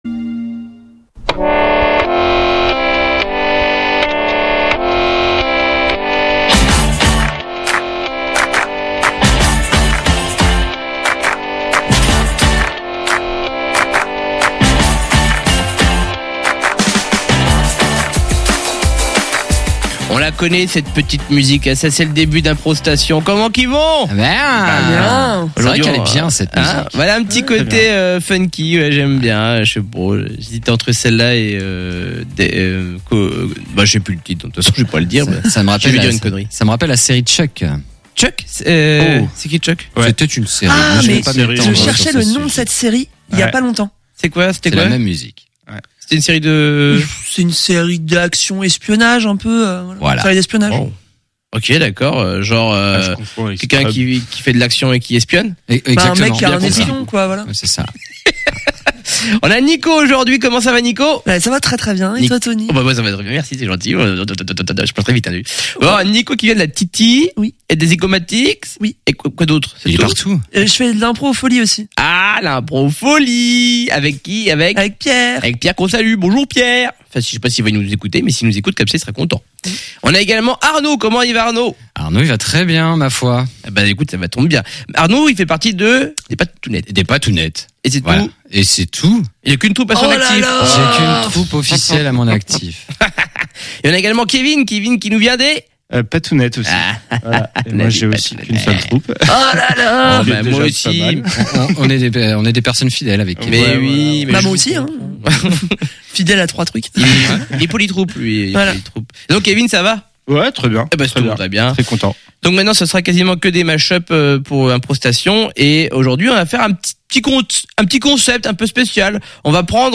Des improvisateurices venant des troupes des Patounets et des Zygomatiks réinvente certaines émissions de la grille de Radio G